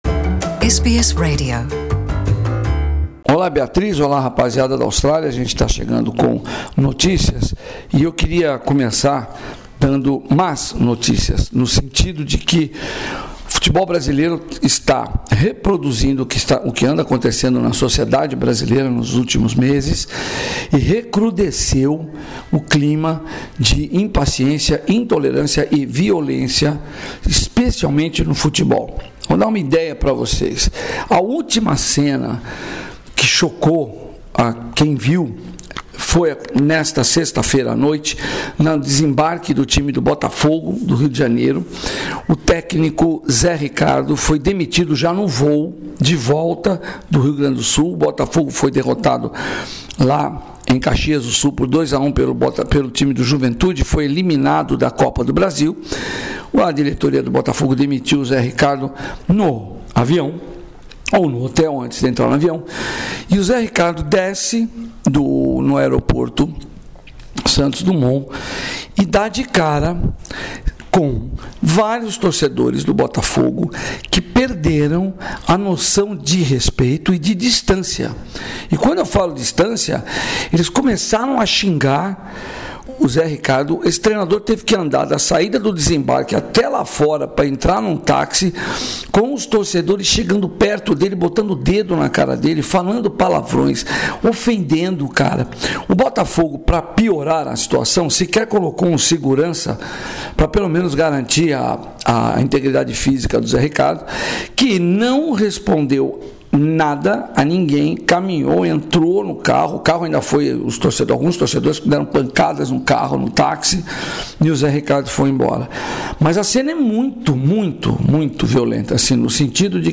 Direto de São Paulo